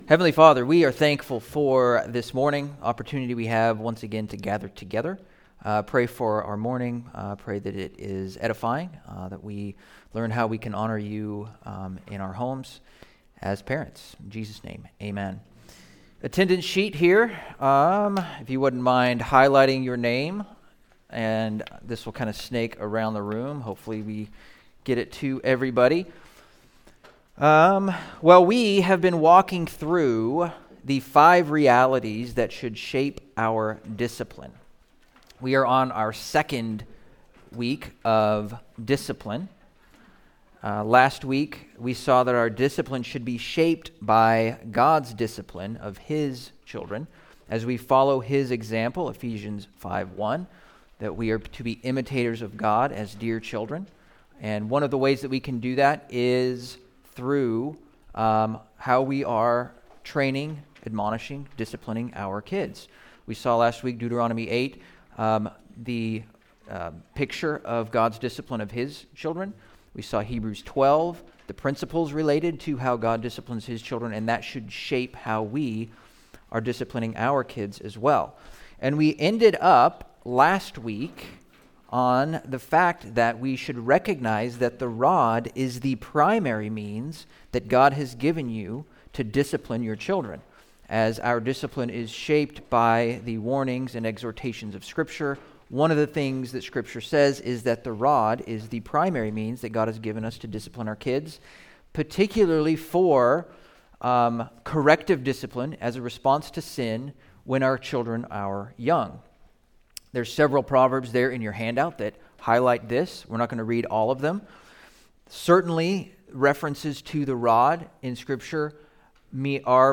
Biblical Parenting Class - 2026